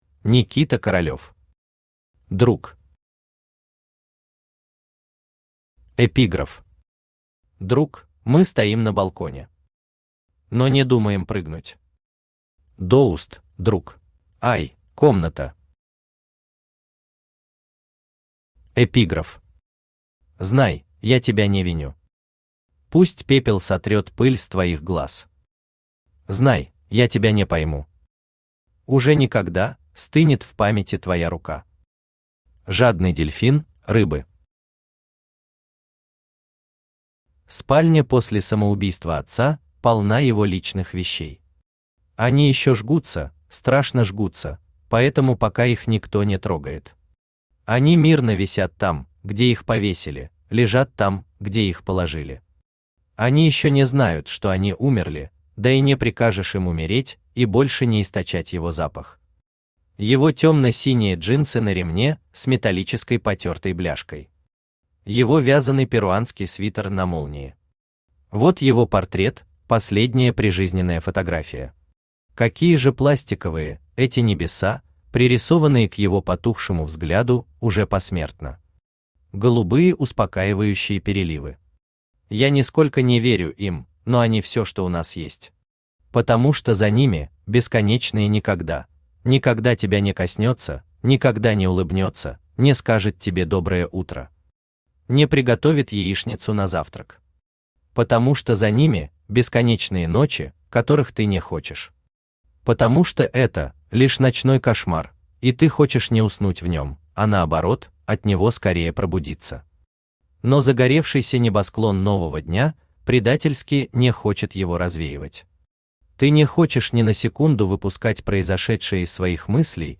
Аудиокнига Друг | Библиотека аудиокниг
Aудиокнига Друг Автор Никита Королёв Читает аудиокнигу Авточтец ЛитРес.